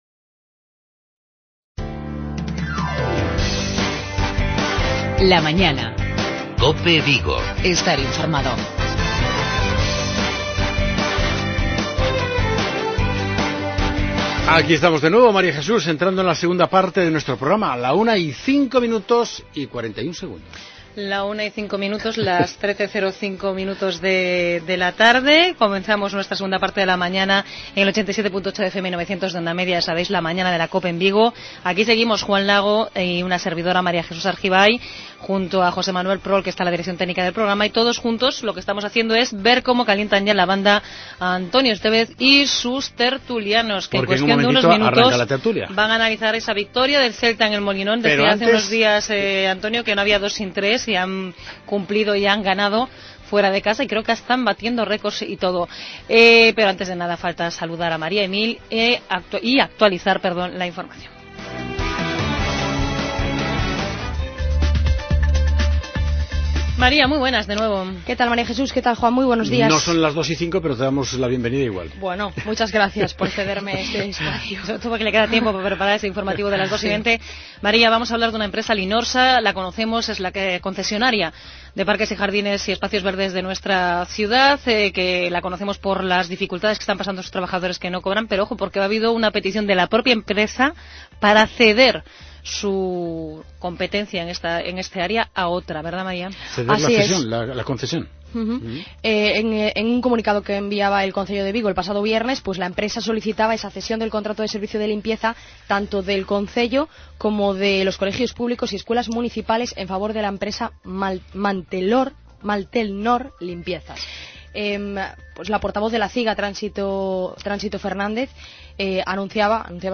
Redacción digital Madrid - Publicado el 11 abr 2016, 14:56 - Actualizado 17 mar 2023, 14:13 1 min lectura Descargar Facebook Twitter Whatsapp Telegram Enviar por email Copiar enlace Tertulia deportiva, repasando la victoria de este pasado domingo cosechada por el Celta en su desplazamiento a Gijón para enfrentarse al Sporting. El equipo está cada vez más cerca de Europa.